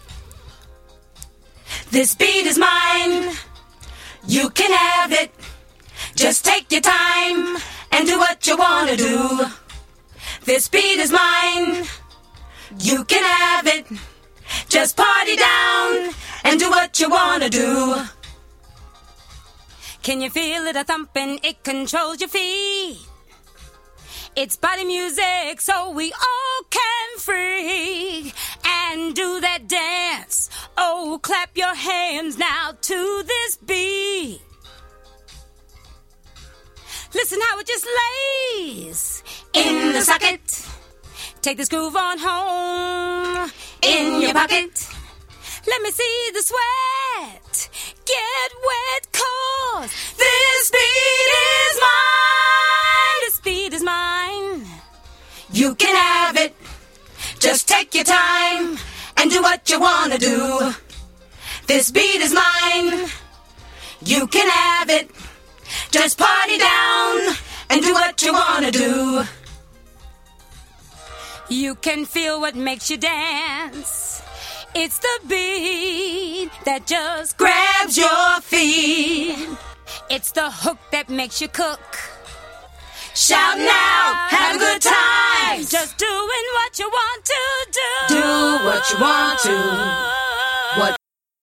Disco Funk